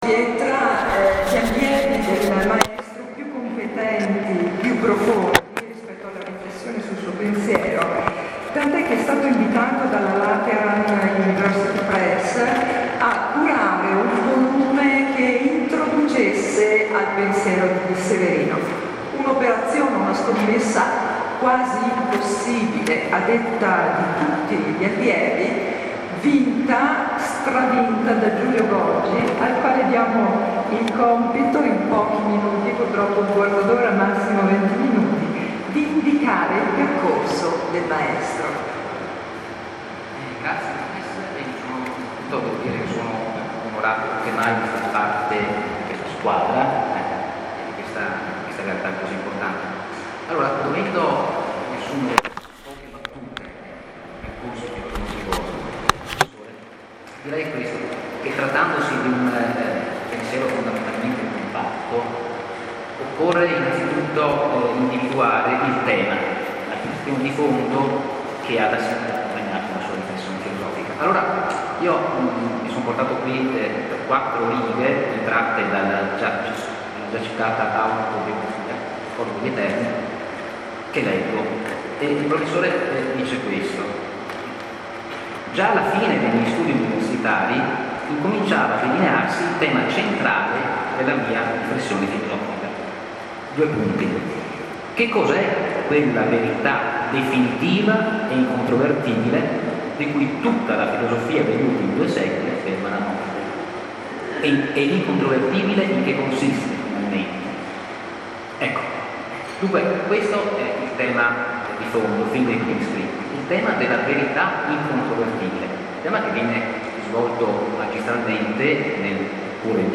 Sorgente: Incontro con il filosofo EMANUELE SEVERINO: presentazione dell’ASES – Associazione Studi Emanuele Severino, presso il salone Vanvitelliano nel Palazzo della Loggia, sede del Comune di Brescia, Sabato 25 febbraio 2017, ore 10-12,30. AUDIO dell’incontro – Il pensiero filosofico di Emanuele Severino